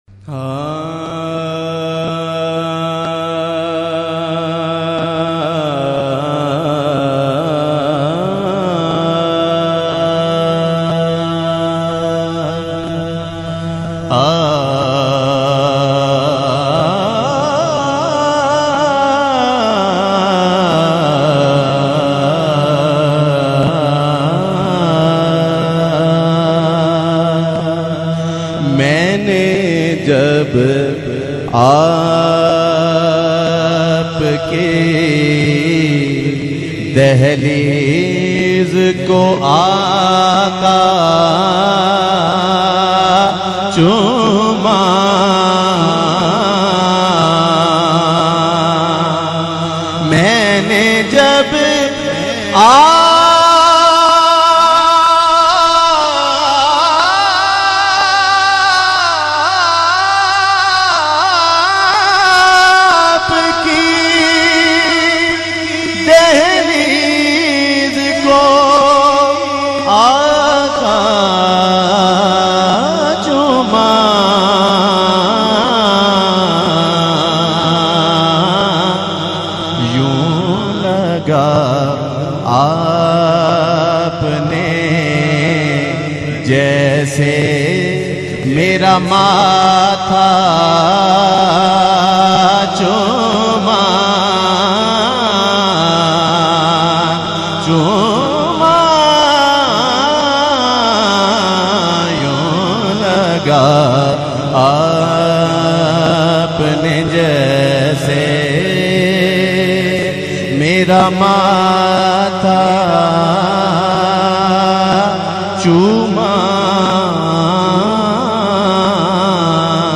in a Heart-Touching Voice